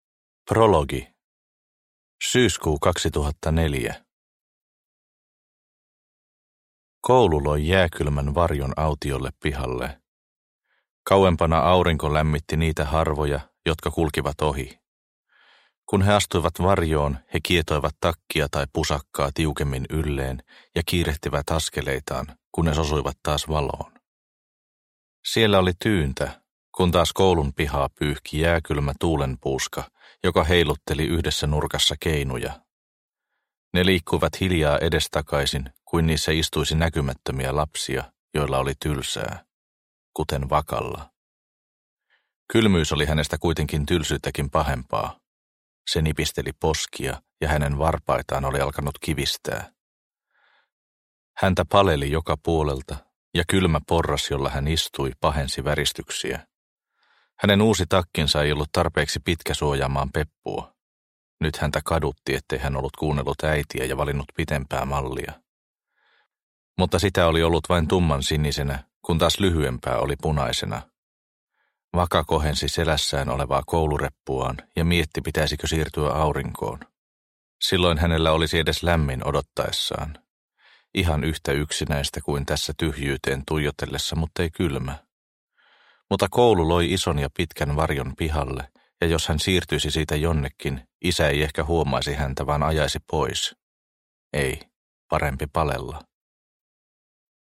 Pyörre – Ljudbok – Laddas ner
Produkttyp: Digitala böcker